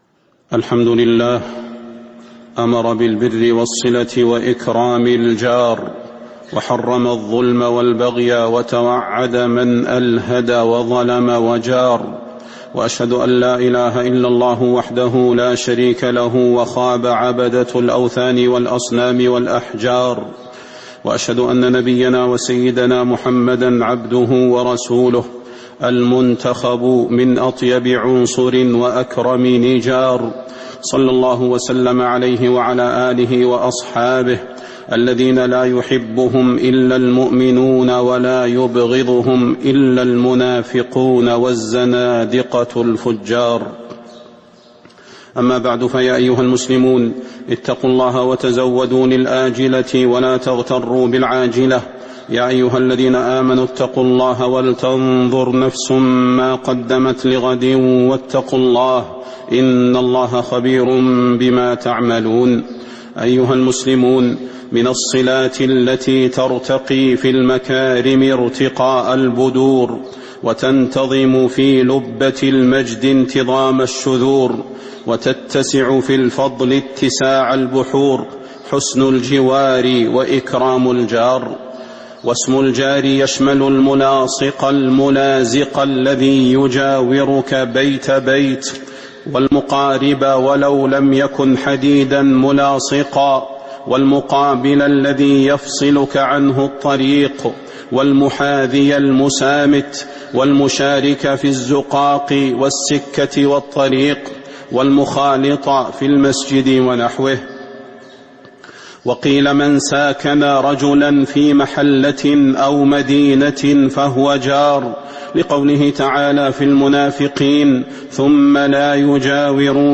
تاريخ النشر ١٣ جمادى الآخرة ١٤٤٤ هـ المكان: المسجد النبوي الشيخ: فضيلة الشيخ د. صلاح بن محمد البدير فضيلة الشيخ د. صلاح بن محمد البدير حقوق الجار